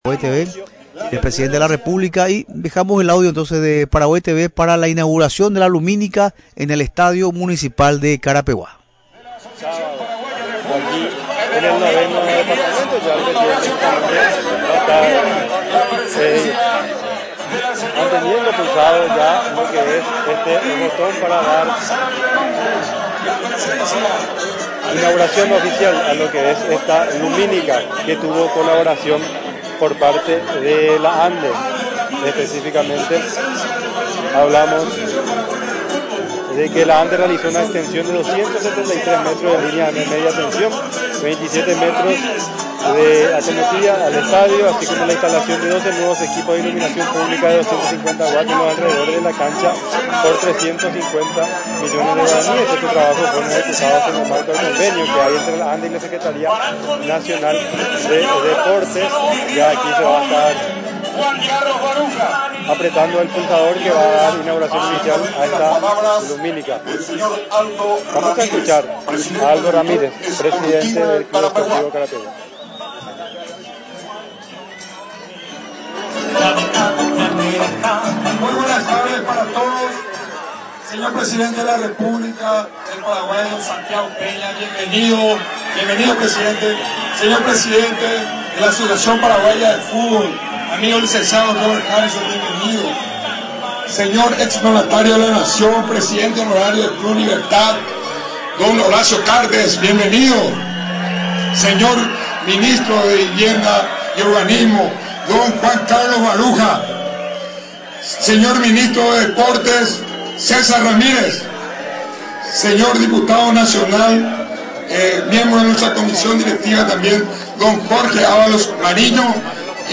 En un acto que se realizó en el entretiempo del juego entre el tricampeón Libertad y el Sportivo Luqueño por la décimo novena jornada del Clausura, se procedió a la inauguración de la lumínica del estadio Municipal de Carapeguá.